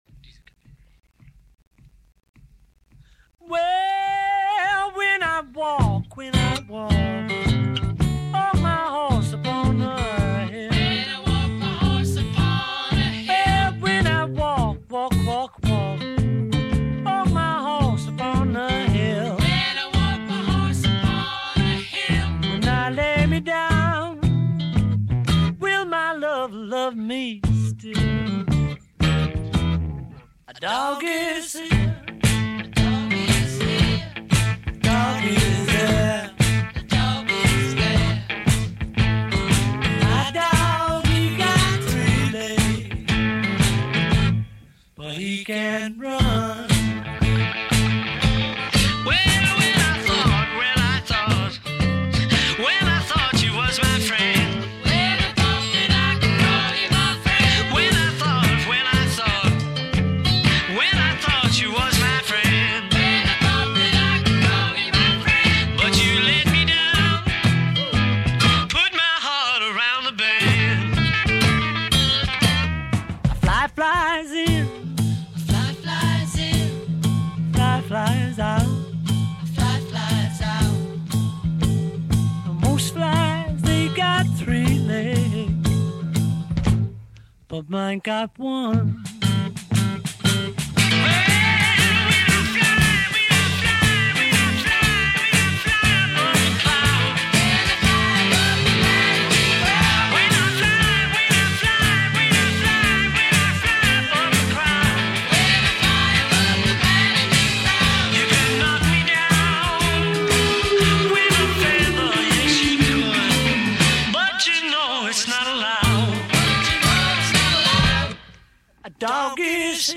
записанный в январе-марте 1971 года в RCA Studios, Нью-Йорк